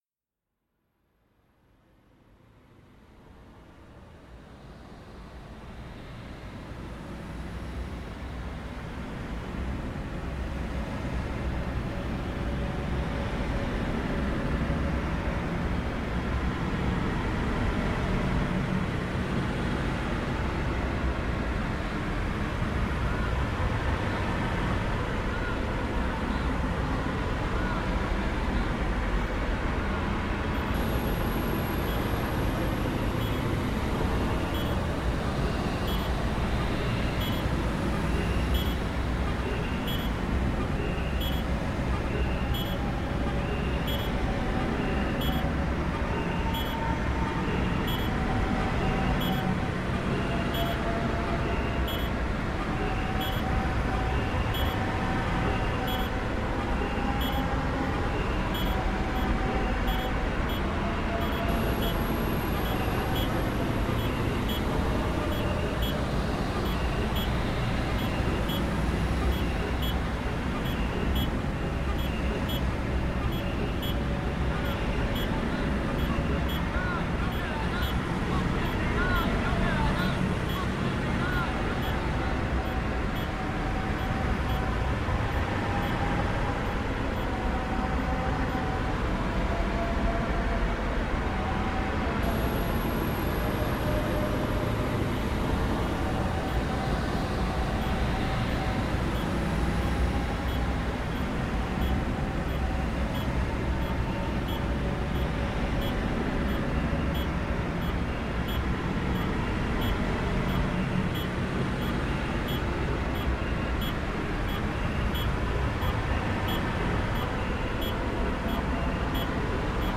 Santiago protest reimagined
In my reimagining I have tried to give each layer random and focussed moments; moments of survival and moments of death.
Small serendipitous sounds have been manipulated to form intentional melodies and rhythms, the voice has been reduced to one single lingering phrase and the cacophony has been given the role of a pedal note. At certain points through the piece these sounds are given a greater or lesser focus. All the sounds you hear have come from the original recording.